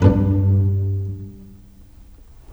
Rock-Pop 09 Pizzicato 08.wav